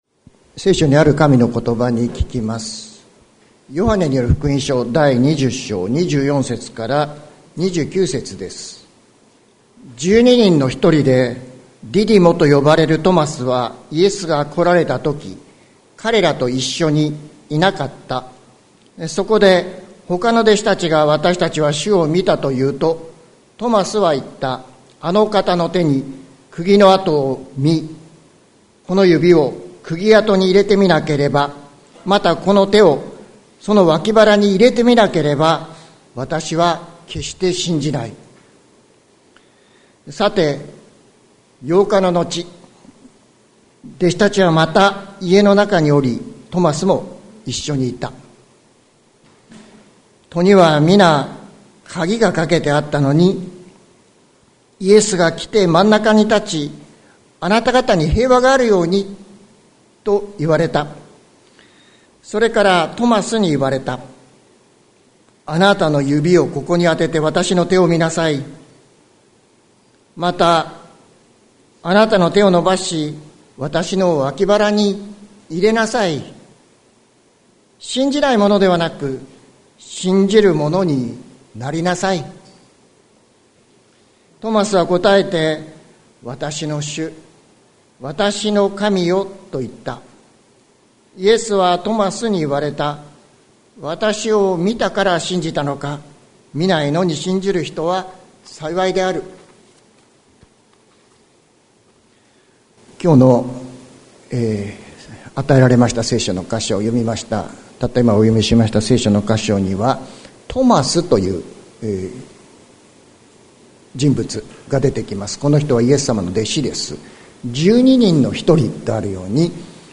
2024年02月25日朝の礼拝「 見ないで信じる人に」関キリスト教会
説教アーカイブ。